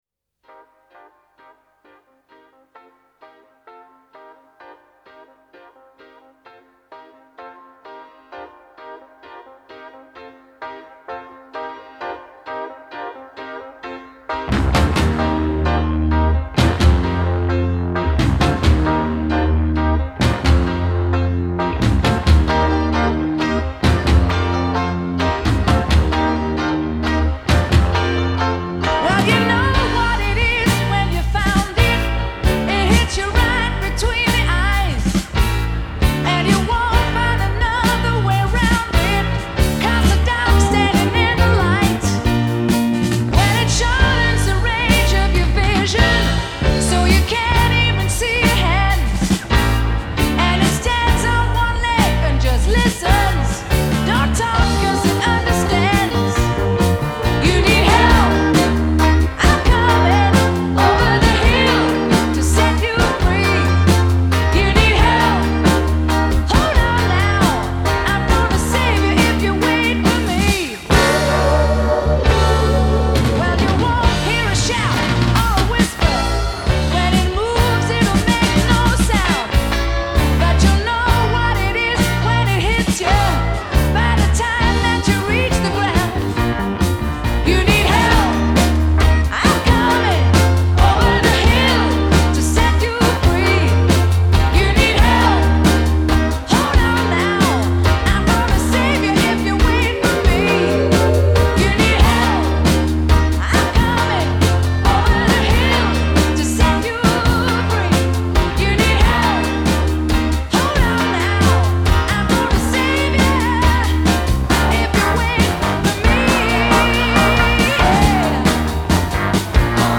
Genre : Soul